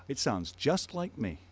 Example 1: focus on `me':
synthesised version